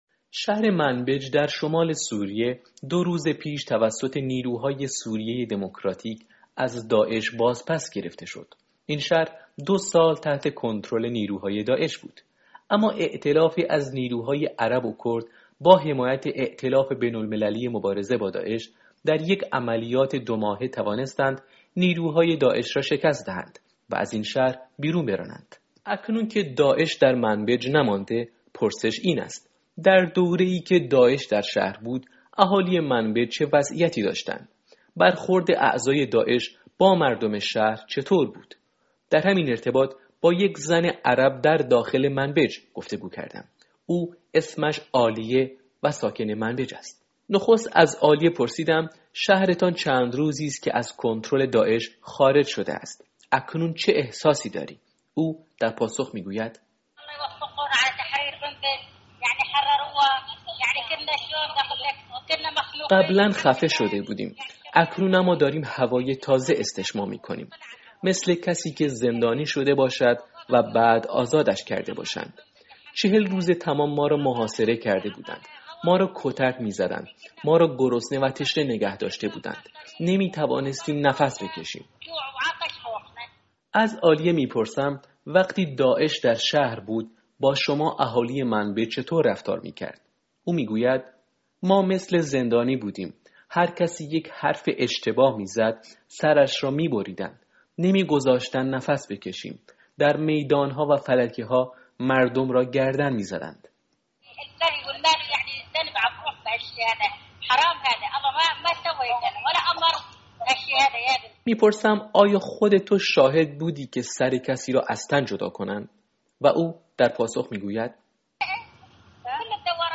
رادیو فردا با یکی از ساکنان منبج، شهر آزاد شده از تصرف حکومت اسلامی گفتگو کرده است.